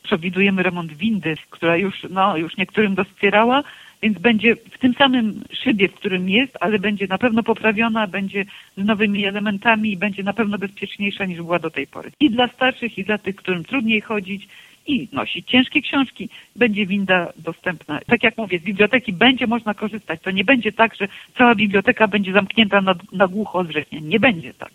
O szczegółach mówiła w poniedziałek (27.07) na antenie Radia 5